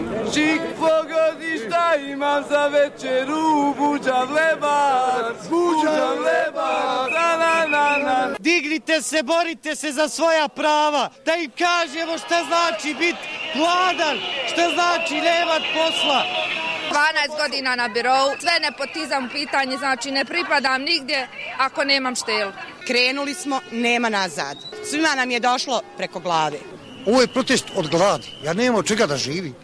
Građani na ulicama